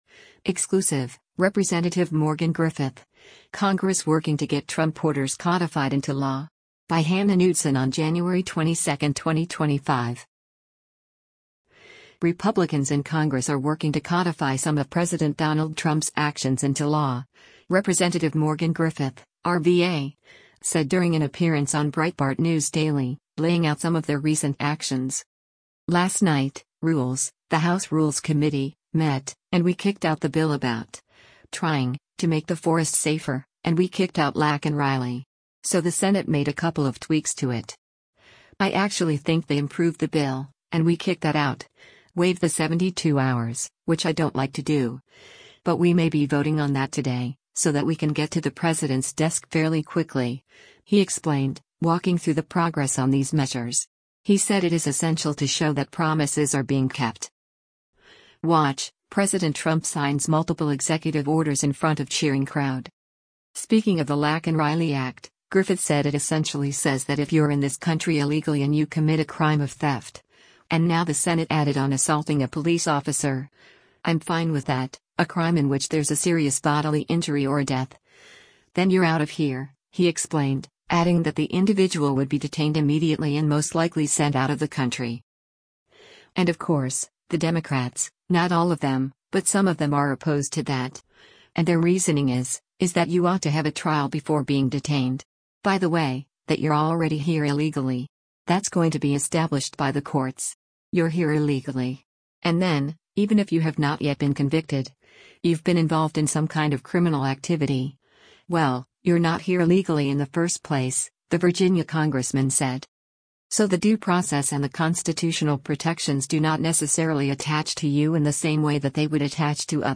Republicans in Congress are working to codify some of President Donald Trump’s actions into law, Rep. Morgan Griffith (R-VA) said during an appearance on Breitbart News Daily, laying out some of their recent actions.
Breitbart News Daily airs on SiriusXM Patriot 125 from 6:00 a.m. to 9:00 a.m. Eastern.